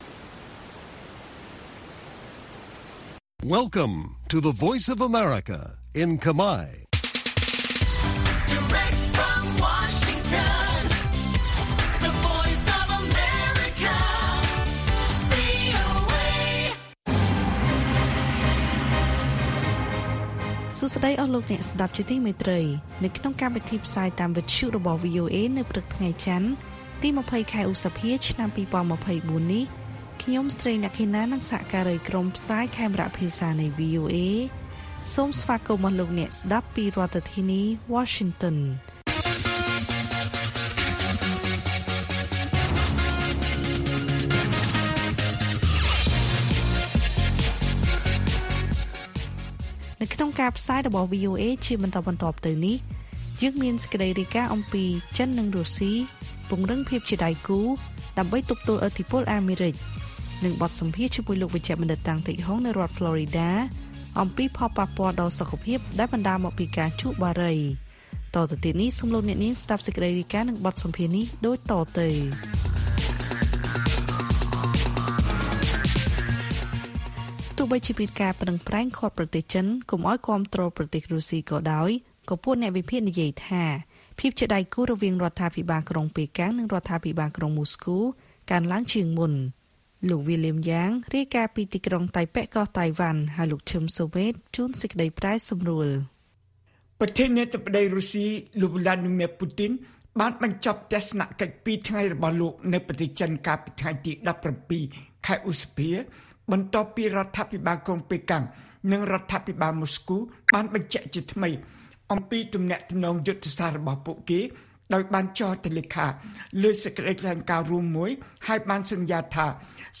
ព័ត៌មានពេលព្រឹក ២០ ឧសភា៖ ចិននិងរុស្ស៊ីពង្រឹងភាពដៃគូដើម្បីទប់ទល់ឥទ្ធិពលសហរដ្ឋអាមេរិក